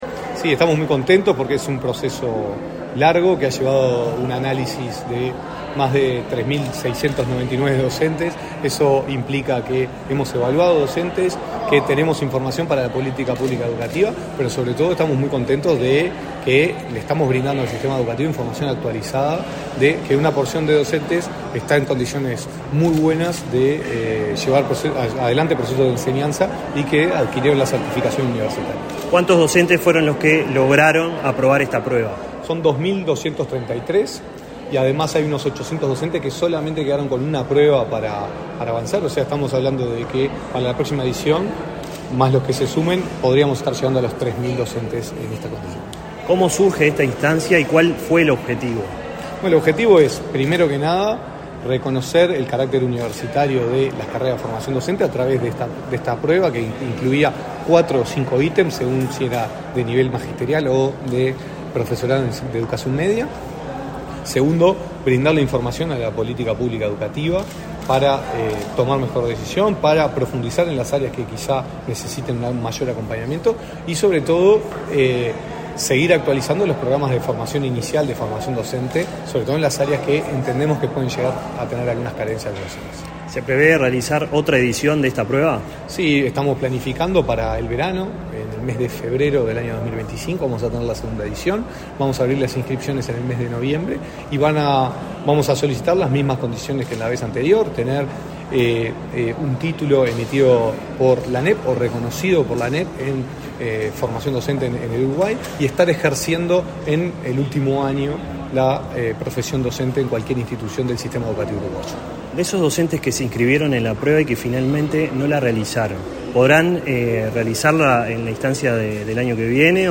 Declaraciones a la prensa del director nacional de Educación, Gonzalo Baroni